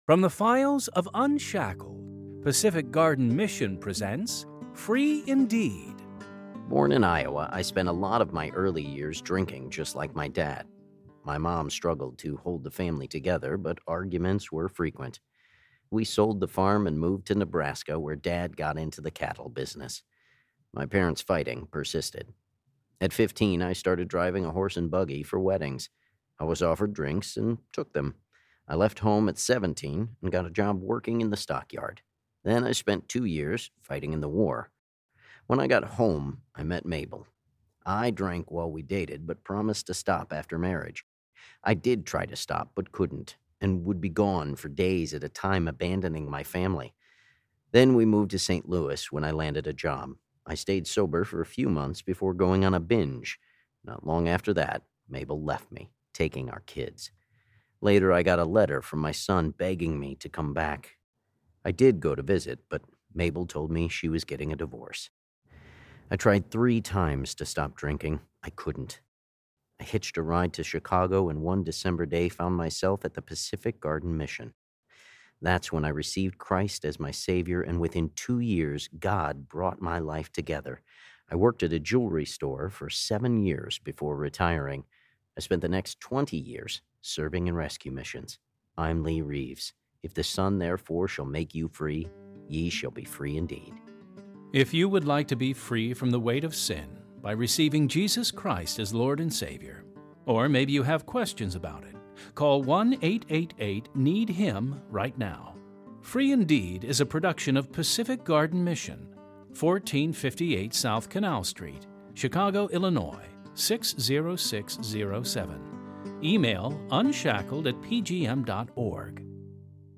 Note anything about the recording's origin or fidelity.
Free Indeed! stories are created from the files of UNSHACKLED! Radio Dramas, and produced by Pacific Garden Mission.